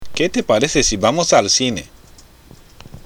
＜発音と日本語＞
（ケテパレセ　シバモス　アルシネ？）